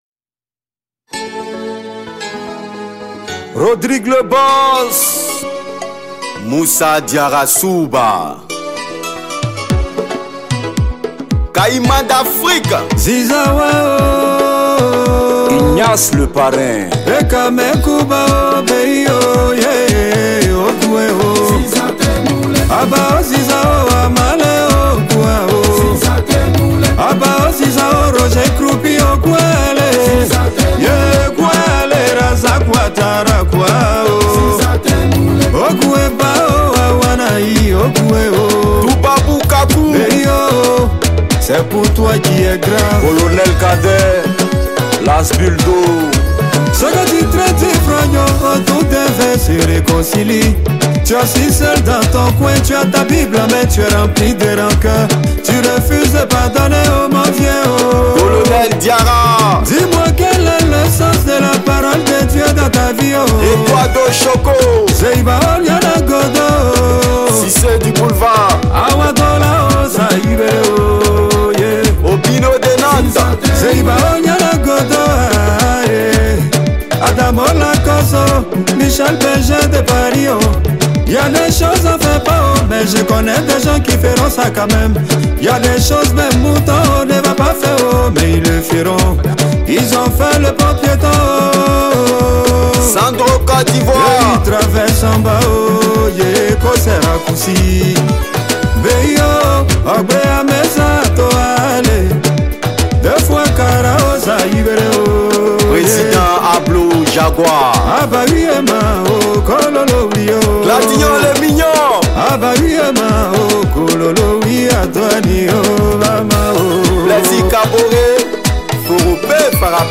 | Zouglou